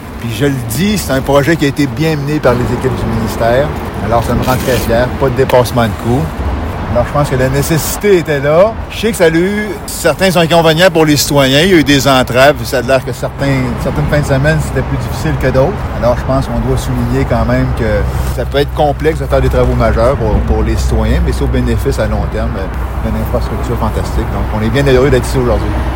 Lors d’une conférence de presse jeudi après-midi, Québec a annoncé que le remplacement de la dalle centrale du pont Laviolette est enfin terminé.